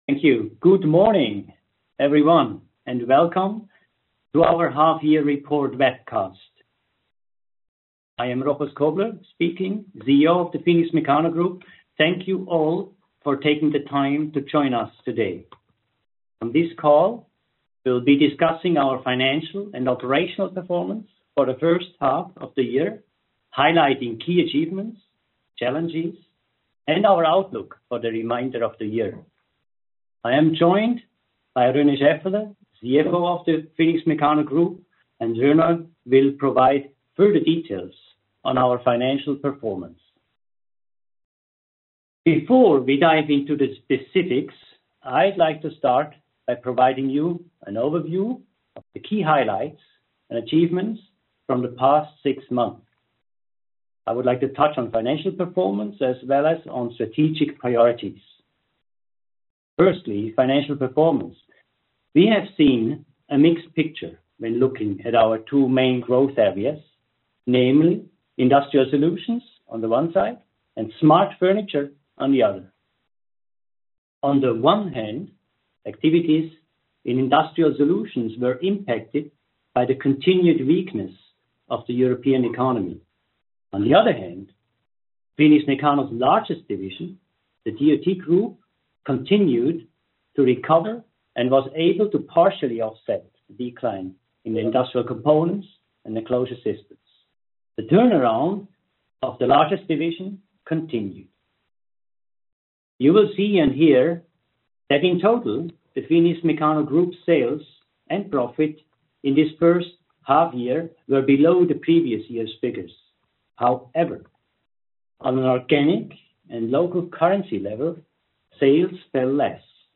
Audio recoding of the webcast from August 15, 2024